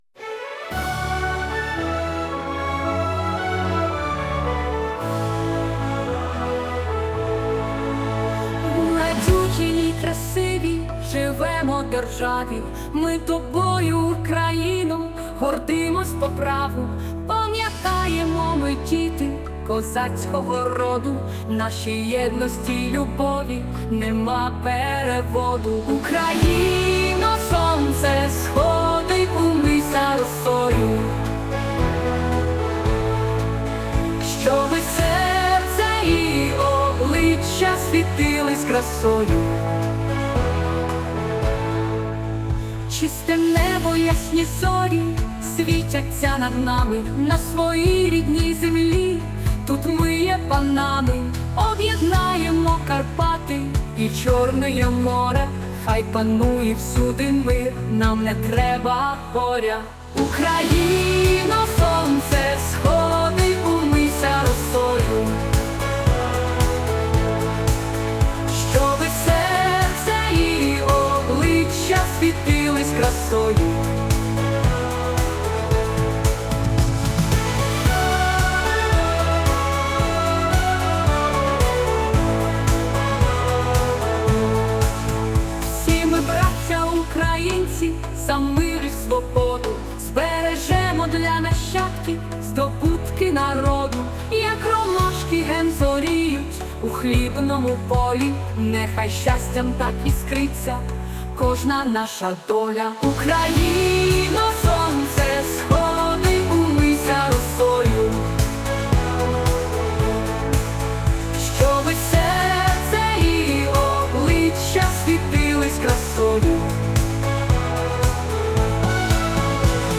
Музика і виконання ШІ
ТИП: Пісня
СТИЛЬОВІ ЖАНРИ: Ліричний